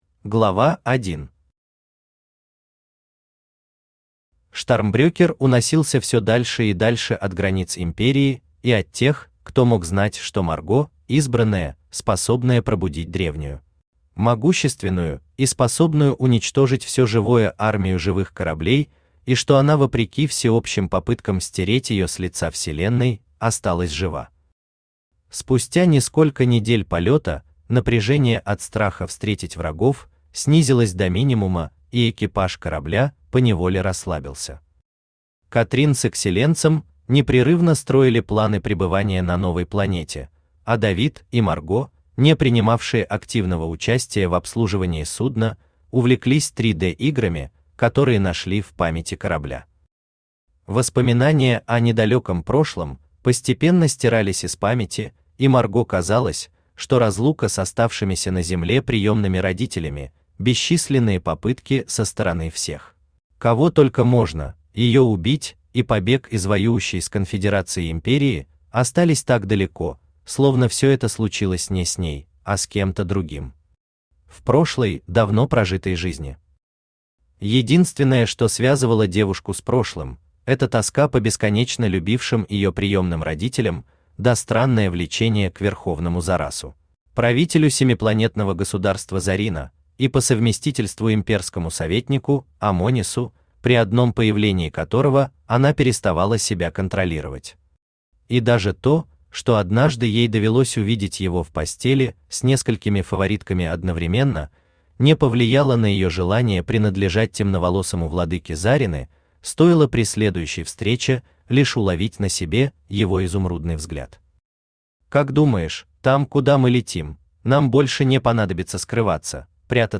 Аудиокнига Империя. Оружие Владык | Библиотека аудиокниг
Оружие Владык Автор Иван Лебедин Читает аудиокнигу Авточтец ЛитРес.